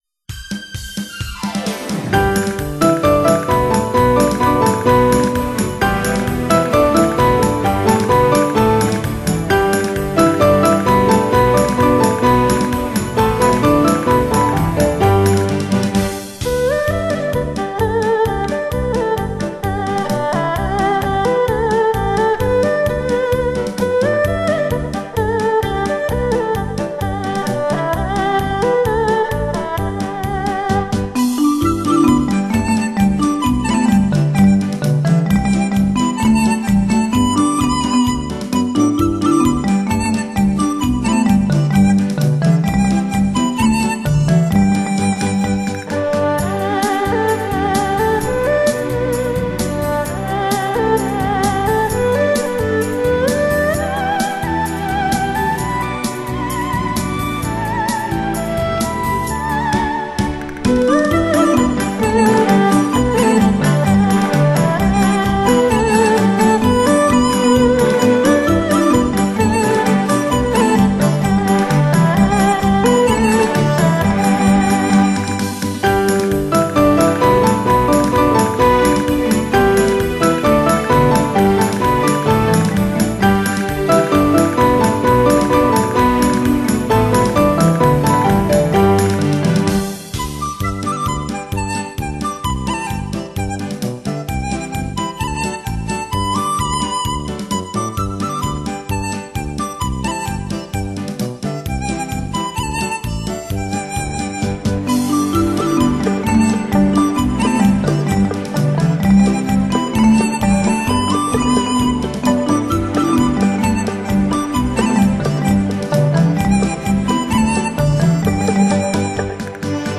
崭新风格的新体验，灵气迫人，悠雅怡然的，清新民乐精品。
碟中音色轻柔细腻、圆润质朴、深情委婉、旋律优美。除了常见的乐器 以外还加入了唢呐、笙、等具有特色的乐器。